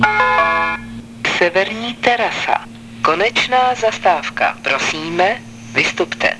Hlášení zastávek ústecké MHD
Většina nahrávek byla pořízena ve voze ev.č. 516.
Na této stránce jsou kvalitnější zvuky, původní web obsahuje nahrávky horší zvukové kvality.